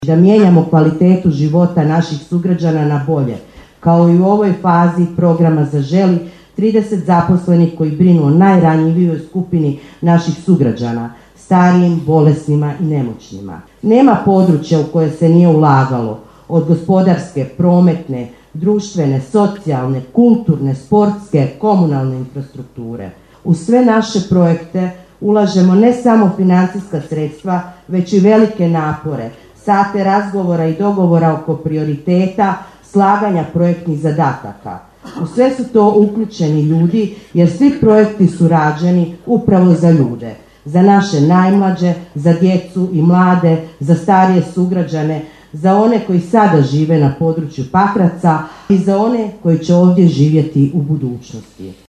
Svečana sjednica Gradskog vijeća Pakraca upriličena je u povodu Dana Grada Pakraca u Hrvatskom domu dr. Franjo Tuđman u Pakracu.